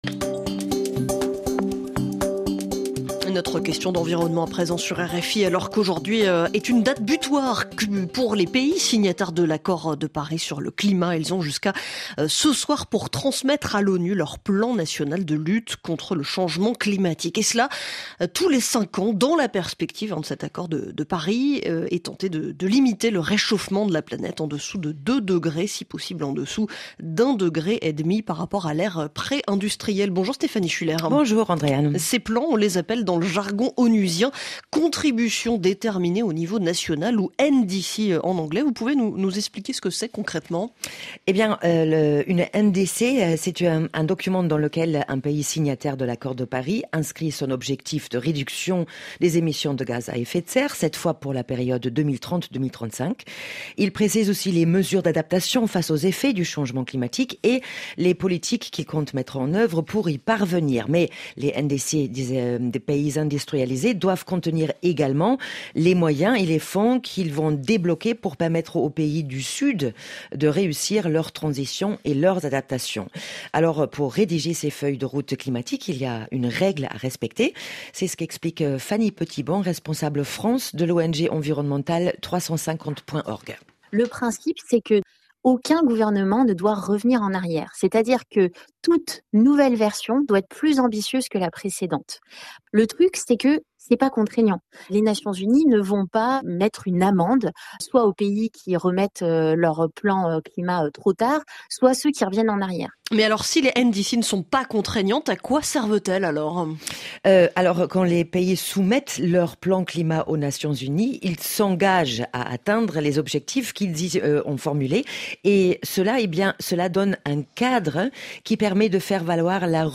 RFI – Questions d’environnement du 10 février 2025, « Changement climatique: qu’est-ce qu’une NDC ? » / 3’29